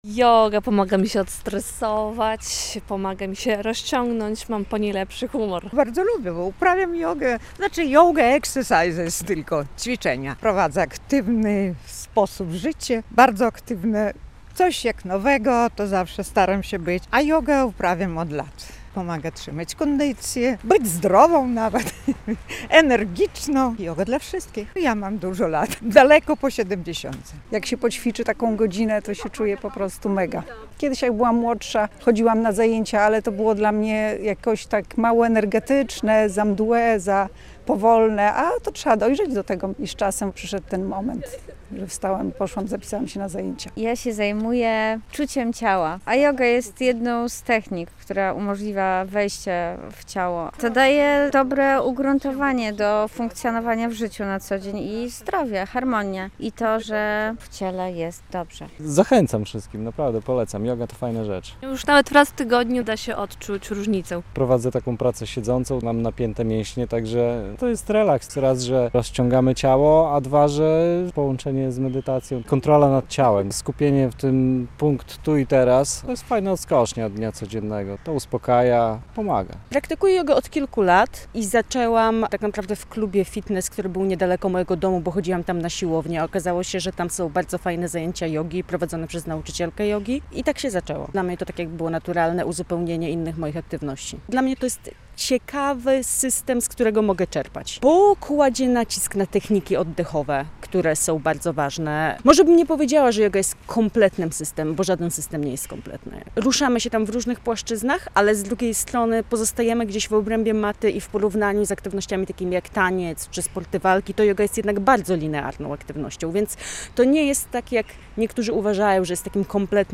Z miłośnikami jogi rozmawiała